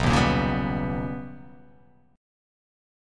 B+M9 chord